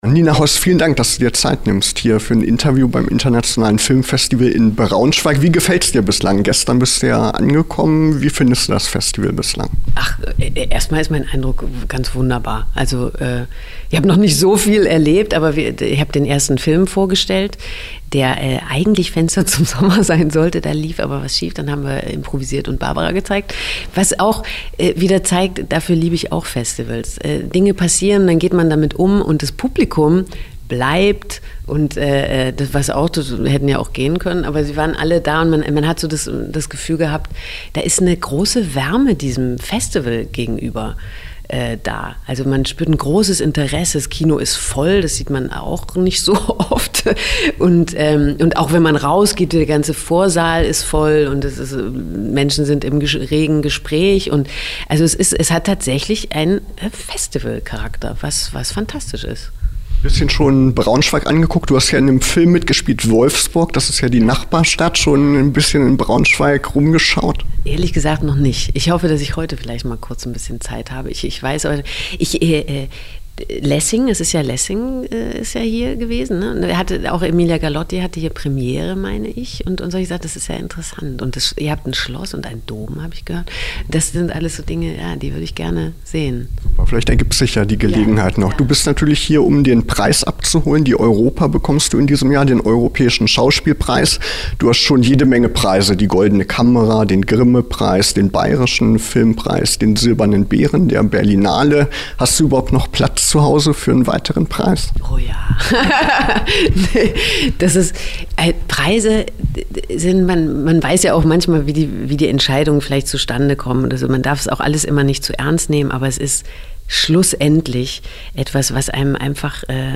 Ihr könnt Euch unser Gespräch mit Nina Hoss hier in voller Länge anhören:
Interview_Nina_Hoss.mp3